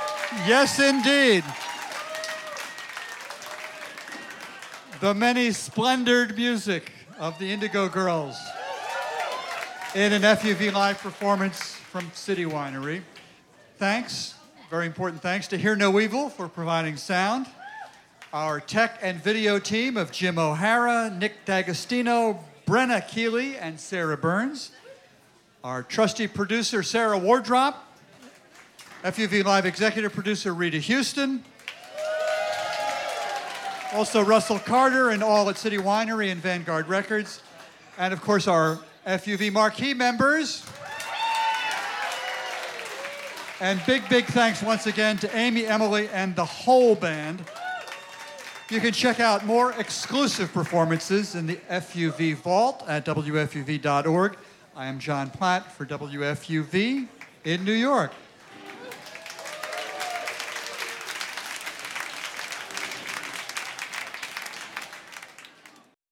lifeblood: bootlegs: 2015-07-23: fuv live at city winery - new york, new york
(recorded from the webcast)